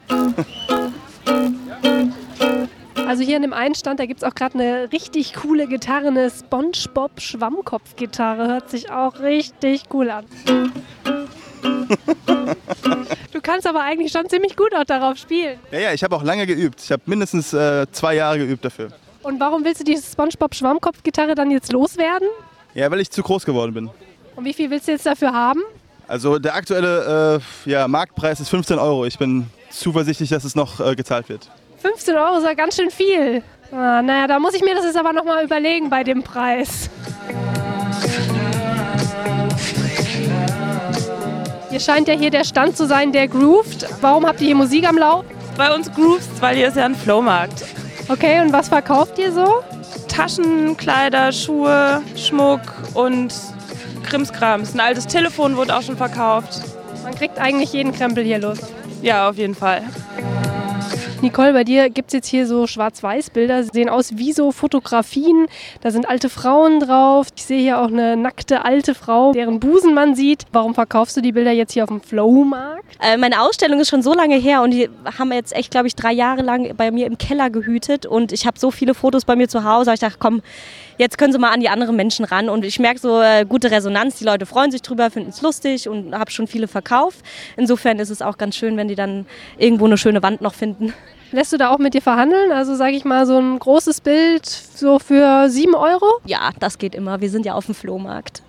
Sie war gestern auf dem Flow-Markt auf der Sommerwerft. Den gibt es immer sonntags ab 15 Uhr.